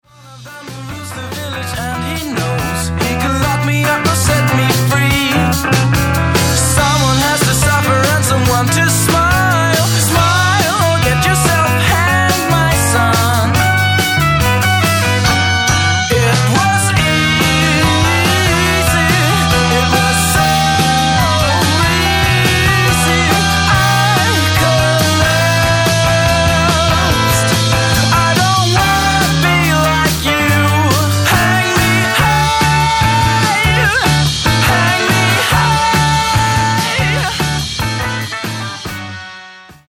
Guitar Pop/Swedish